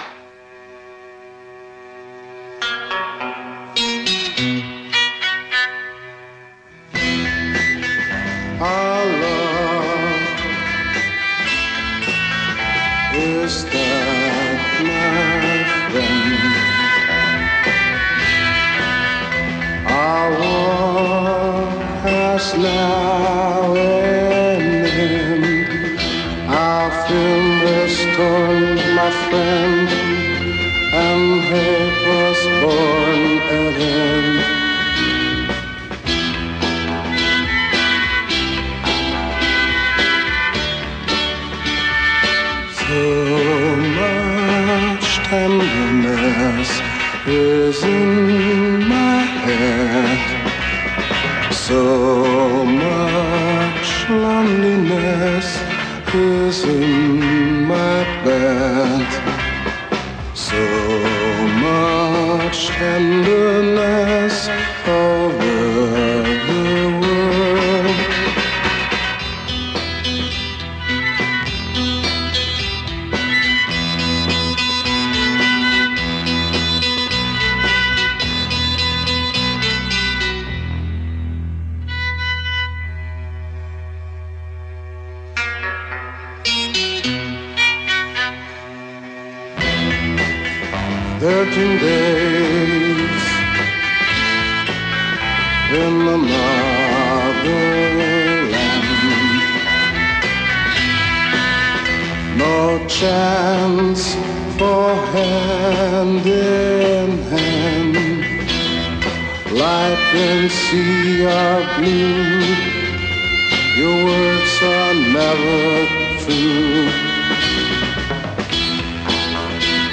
A melancholy ballad
Melancholy, bizarre, and full of, if you will, tenderness.